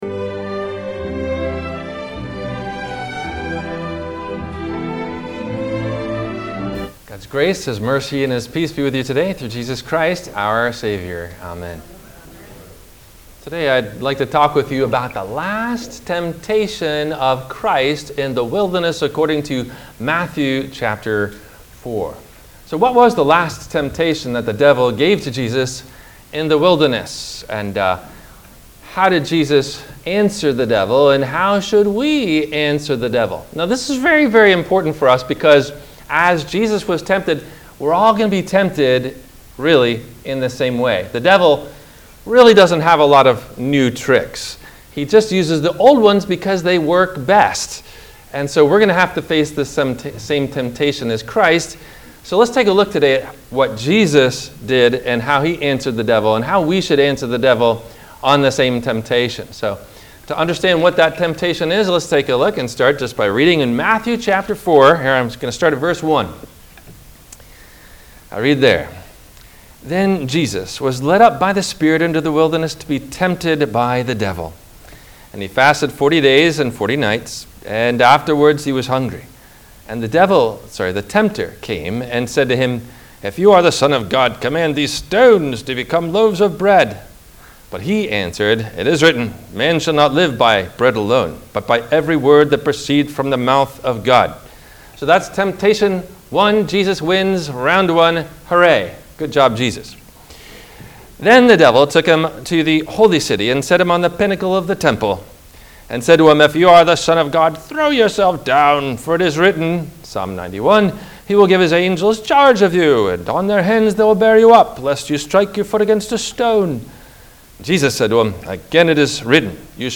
What’s It Profit A Man To Gain The World and Forfeit His Life? – WMIE Radio Sermon – August 19 2024